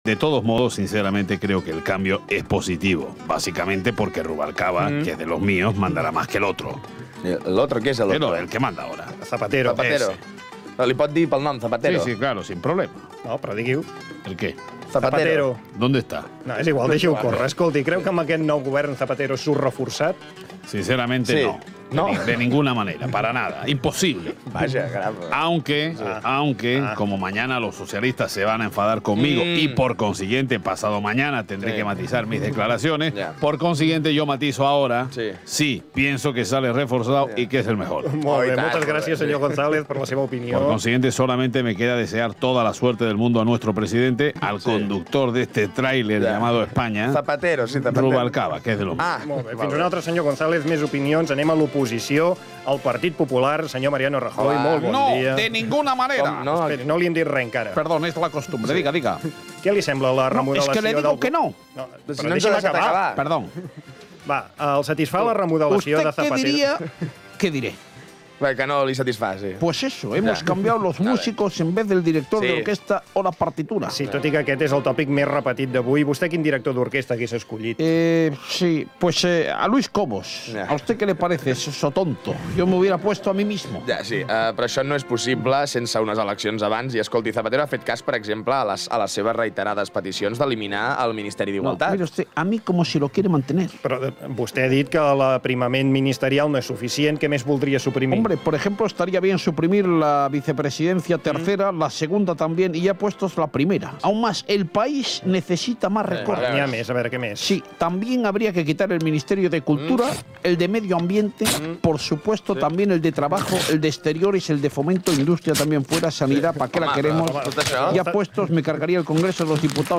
Espai "Problemes domèstics". Els peronatges polítics espanyols (imitacions) valoren, el dia després, el canvi en el govern socialista espanyol fet pel seu president Rodríguez Zapatero.
Entreteniment